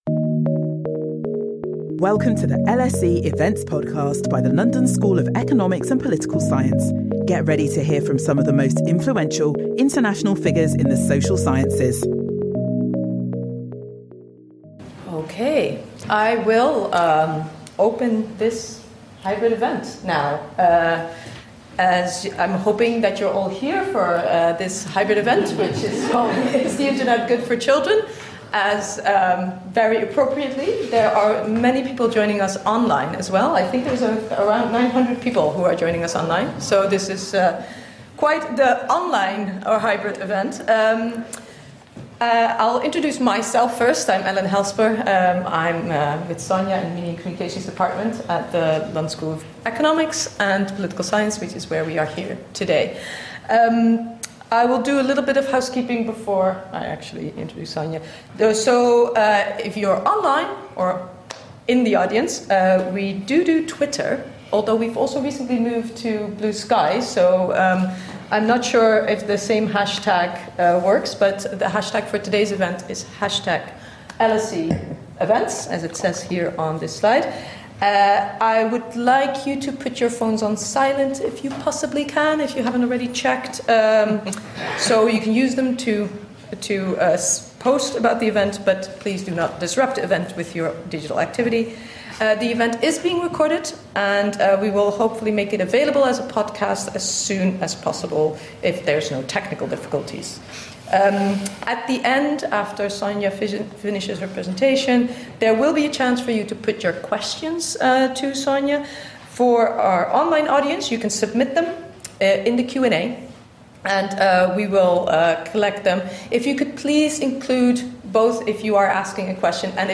Public anxiety about children’s digital lives and wellbeing is reaching a fever pitch, marking a notable turnaround from the decades-long efforts to ensure children are fully digitally included, literate and empowered. While arguments rage over what’s wrong with ‘screen time,’ ‘online harms,’ and data-driven forms of exploitation, this lecture will examine how a children’s rights lens can help steer an evidence-based path towards better digital futures for children.